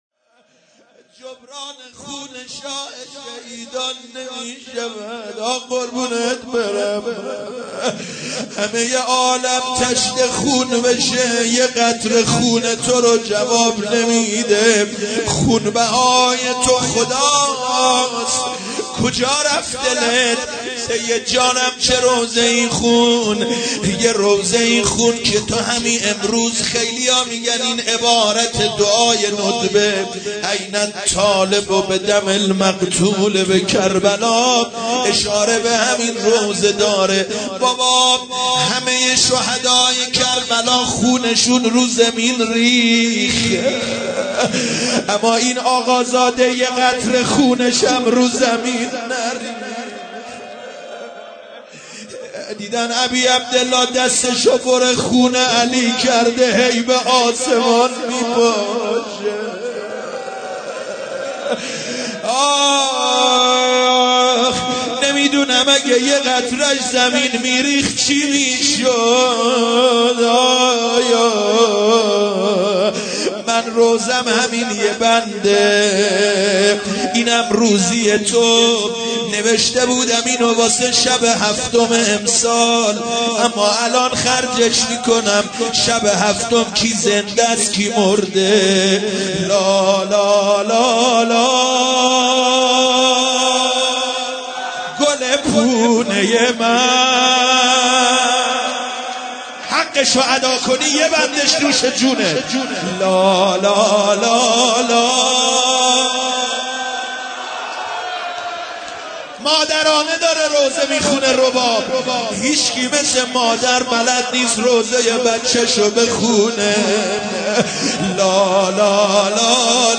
2 روضه امام حسین(علیه السلام)